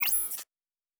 Additional Weapon Sounds 4_3.wav